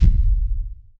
No background noise, dry studio recording. 0:10 deep resonant bass drum with reverb 0:02 Heavy, resonant, meaty stomp. A deep, low-frequency impact sound, simulating a massive, hooved creature striking a hard ground surface. Must have a lingering, powerful echo[Pitch: Very Low.
heavy-resonant-meaty-stom-su6pvvgb.wav